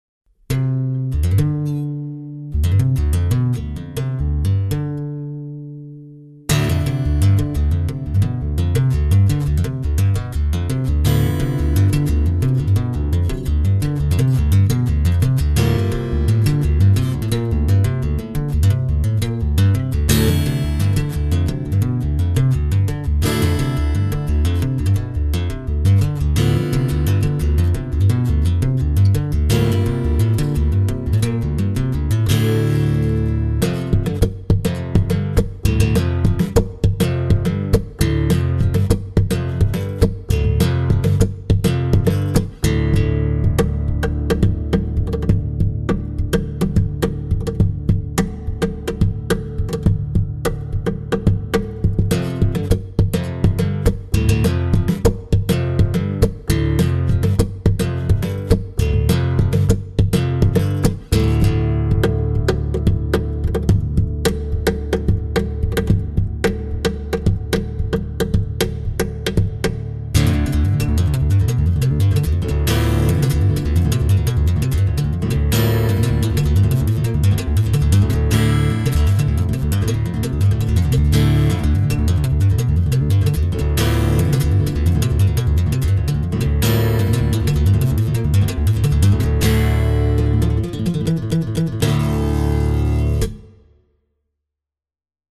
Welcome to the Austrian guitar site featuring: